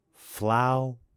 描述：词："穿，"用男声。用舒尔SM57话筒录音。
声道立体声